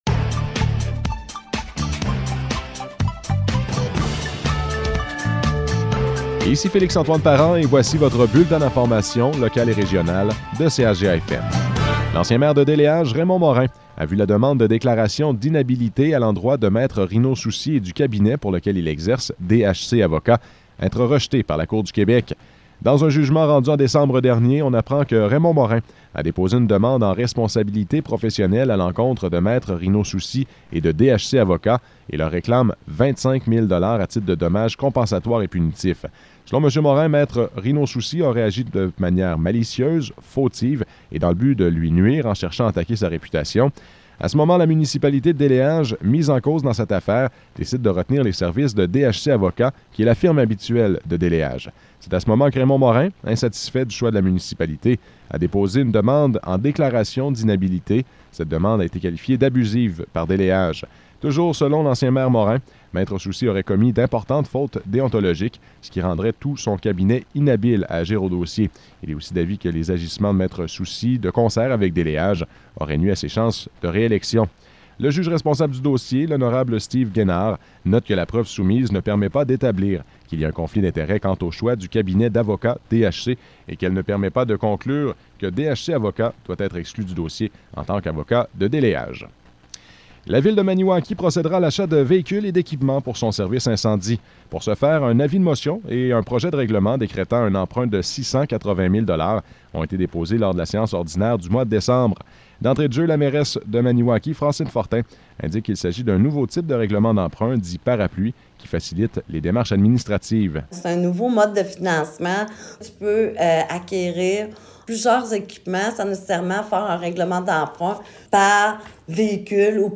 Nouvelles locales - 7 janvier 2022 - 16 h